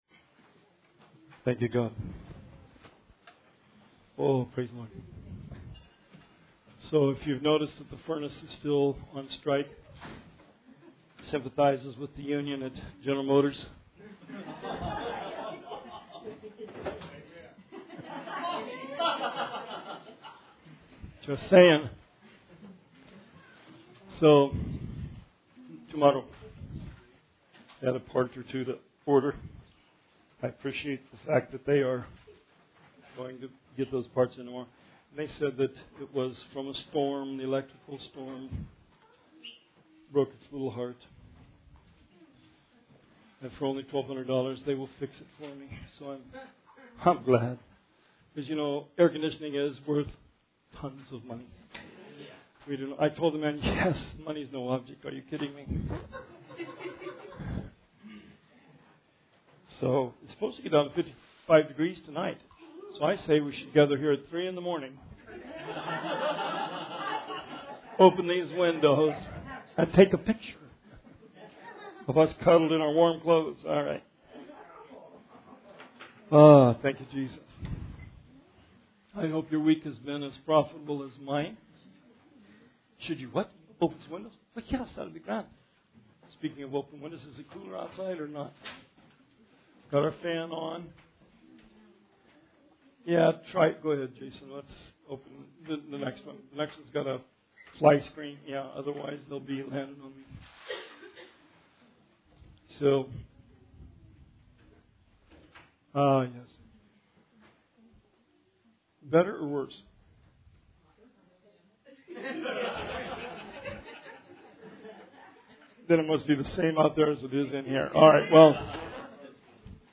Bible Study 9/25/19